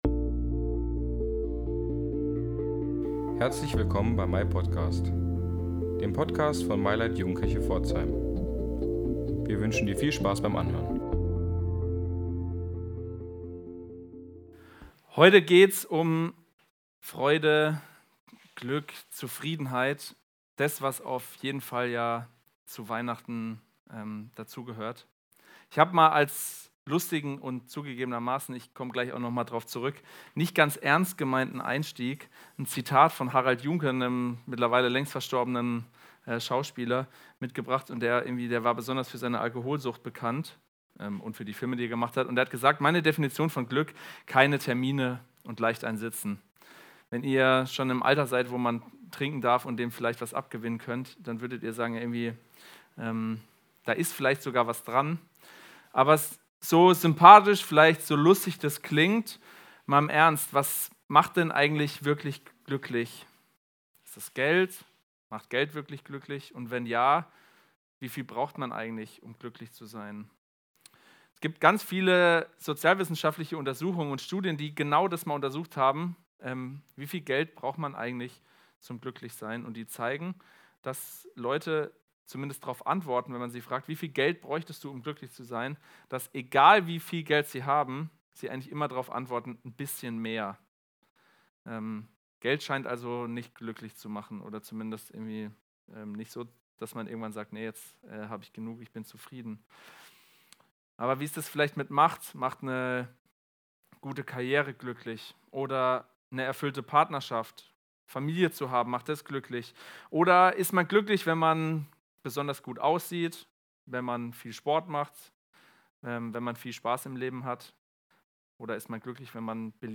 Gottesdienst im Wohnzimmer vom 15. Dezember 2024 kannst du entdecken